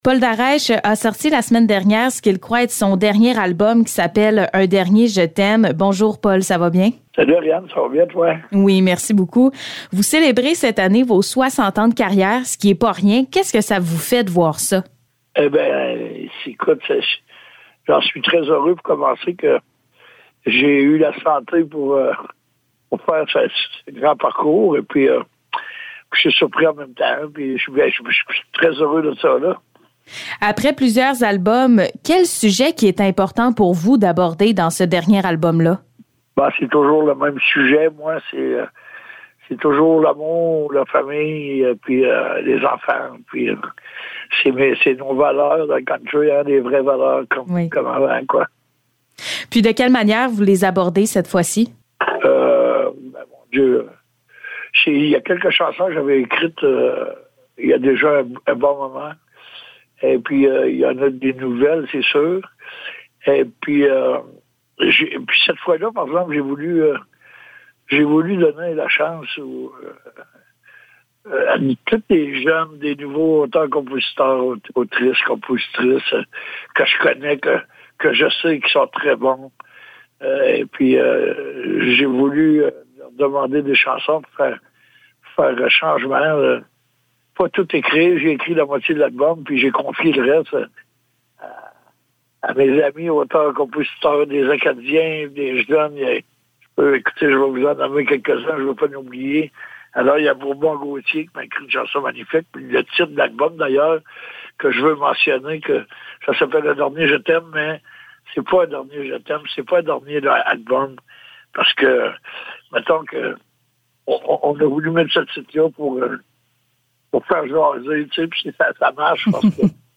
Entrevue Paul Daraîche
Entrevue avec Paul Daraiche concernant son nouvel album, un dernier je t’aime.